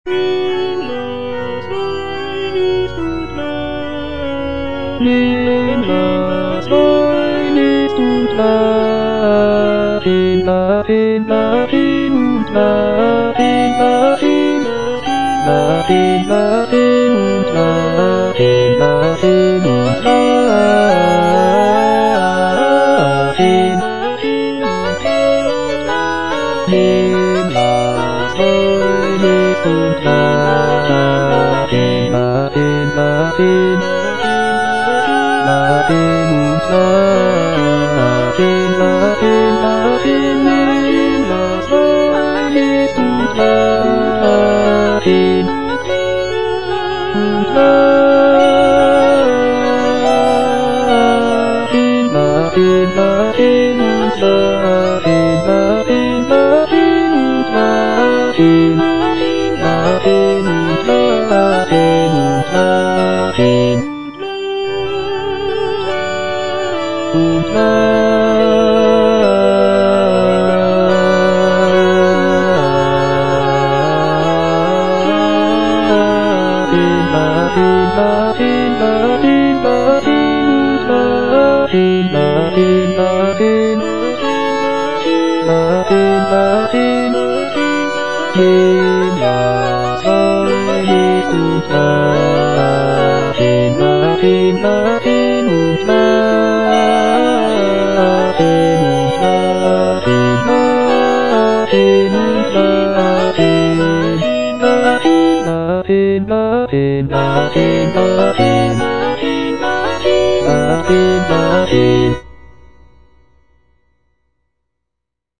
Cantata
Bass (Emphasised voice and other voices) Ads stop